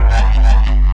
Bass 1 Shots (109).wav